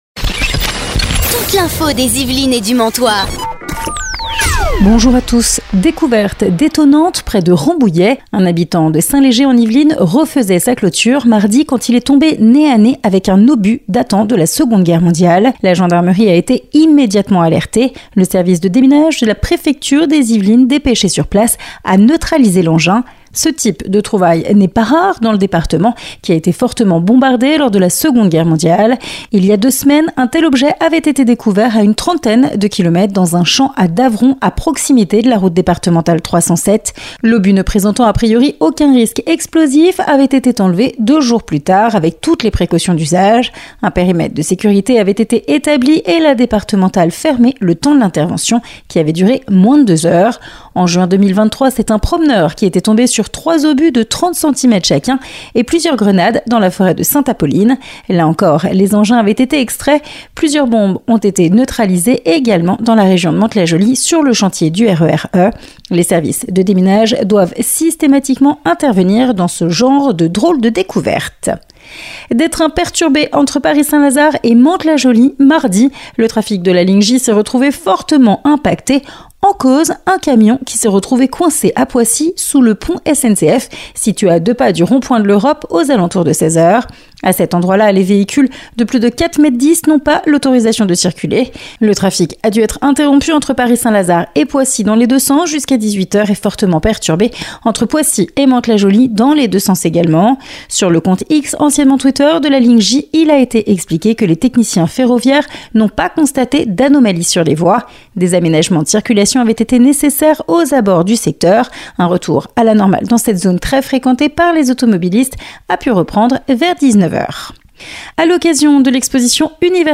(Début de l'interview à 3’21)